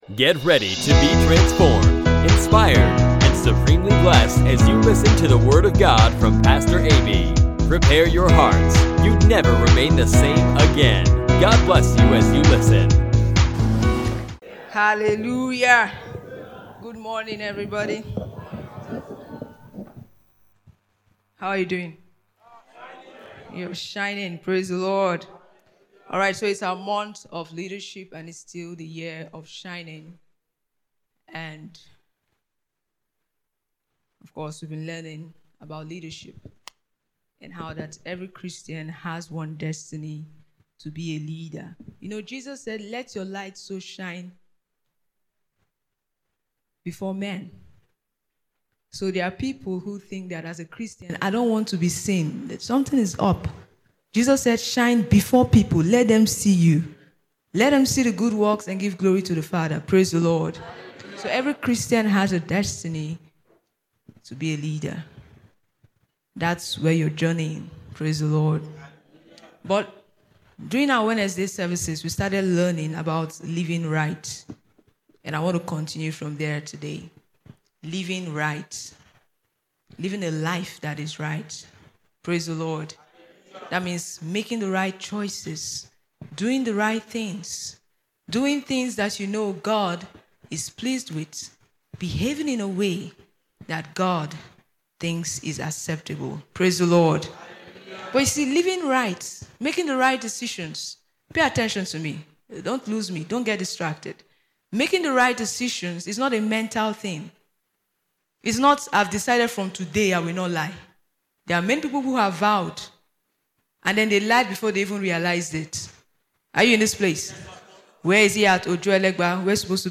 Pastor teaches on right living